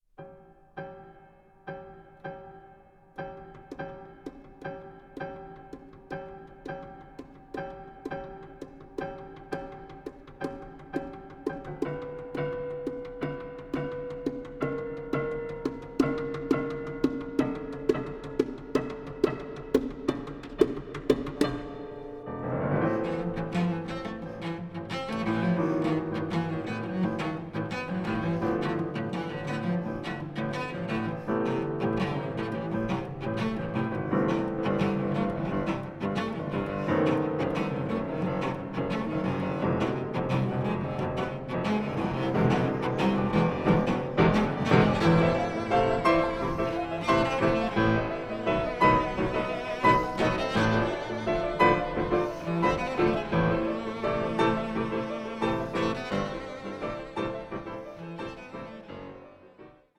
Konzert für Cello und Klavier
Cello
Klavier